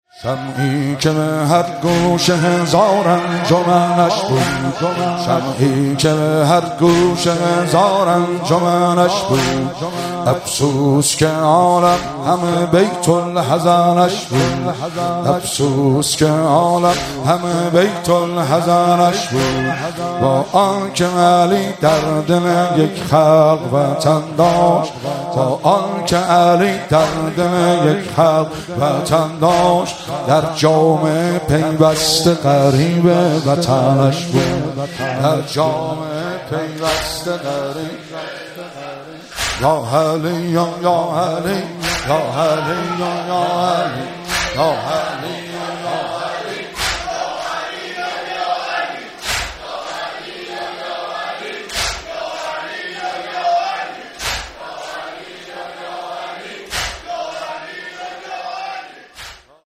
مراسم مناجات خوانی و احیای شب بیست و یکم و عزاداری شهادت حضرت امیرالمومنین علی علیه السلام ماه رمضان 1444